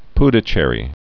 (pdə-chĕrē, -shĕr-)